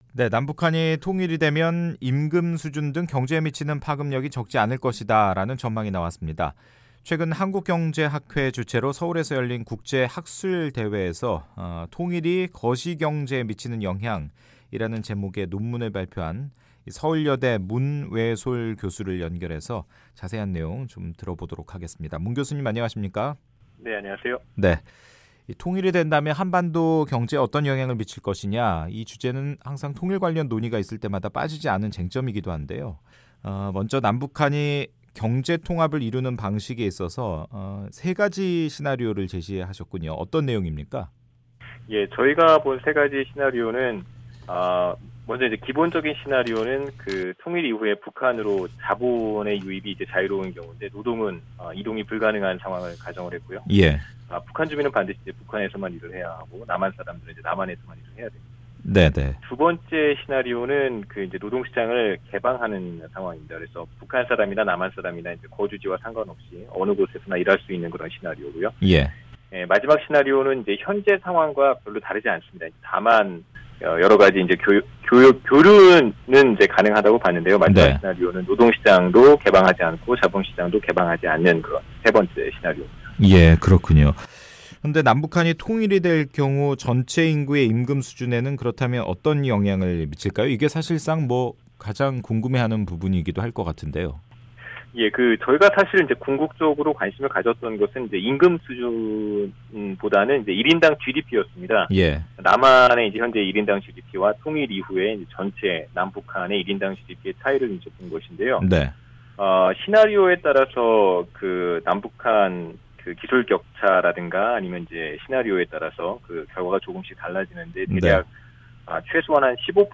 인터뷰 오디오